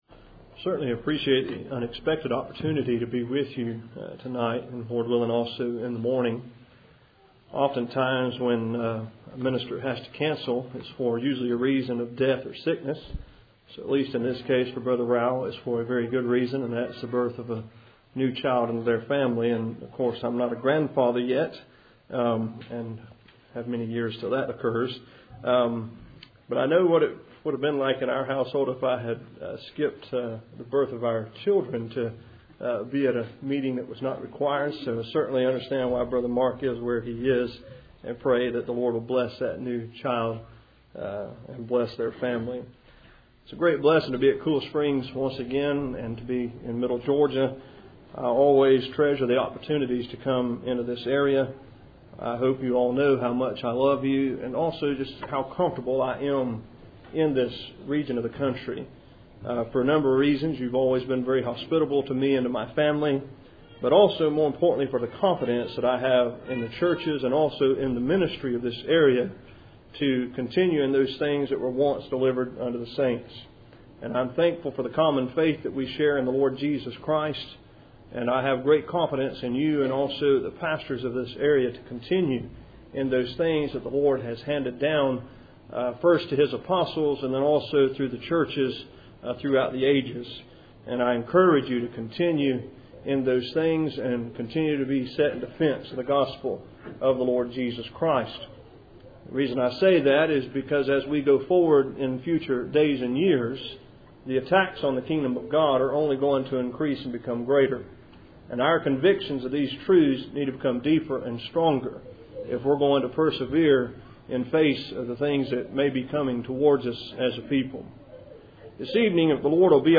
Matthew 22:0 Service Type: Cool Springs PBC March Annual Meeting %todo_render% « Transparency